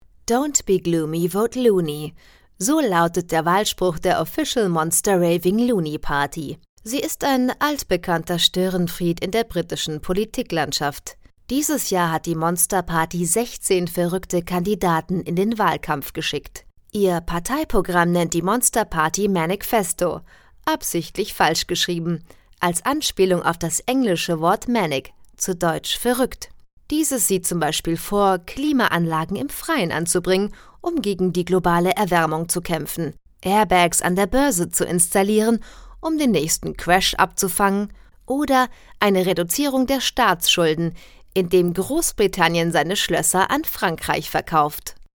Ich bin Profi-Sprecherin und spreche für Sie Werbung, Audio Ads, Dokumentation, E-Learning, Zeichentrick, Jingles, Spiele.
Sprechprobe: eLearning (Muttersprache):